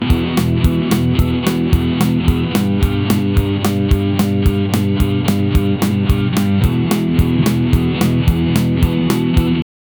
игра на рок-гитаре, под ритм барабана